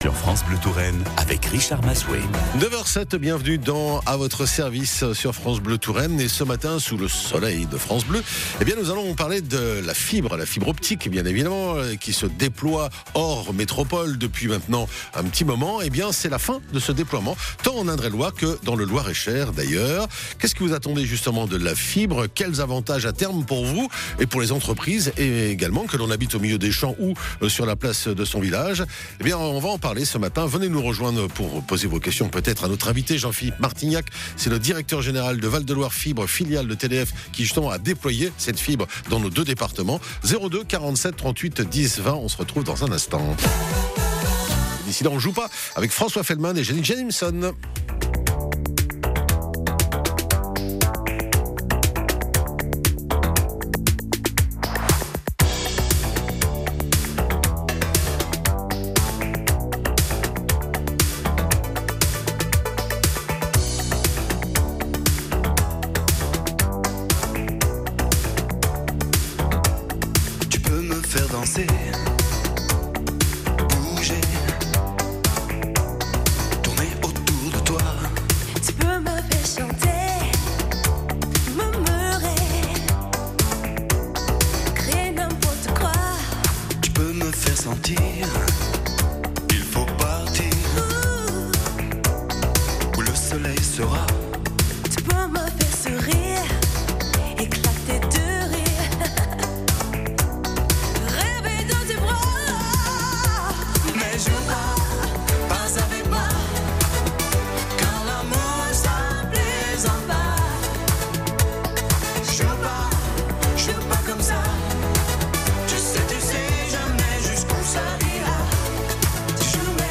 Val de Loire Fibre au micro de radio France Bleu Touraine